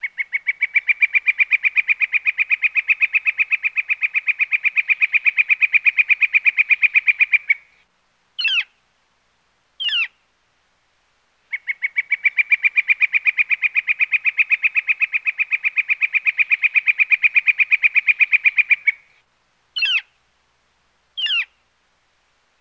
Bird, Flicker; Rhythmic Chirps, Close Perspective.